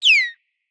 Minecraft Version Minecraft Version latest Latest Release | Latest Snapshot latest / assets / minecraft / sounds / mob / dolphin / idle_water8.ogg Compare With Compare With Latest Release | Latest Snapshot
idle_water8.ogg